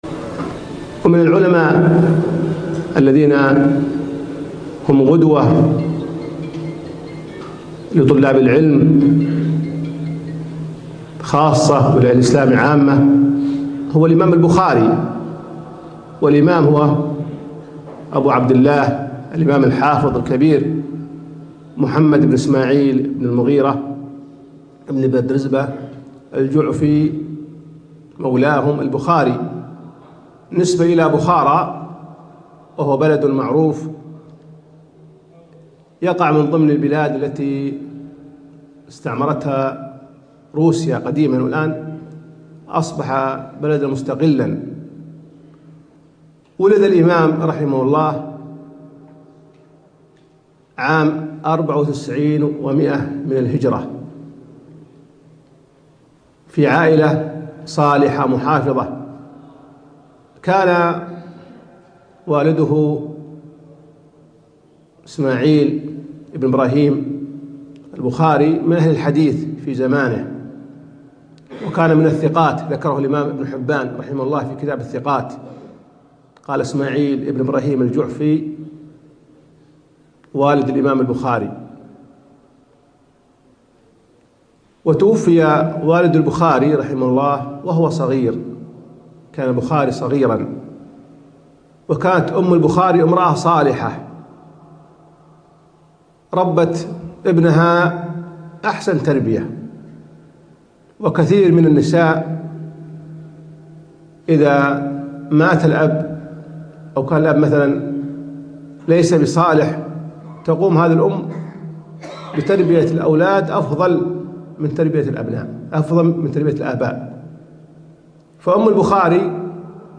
محاضرة - سيرة الإمام البخاري